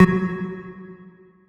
key-delete.wav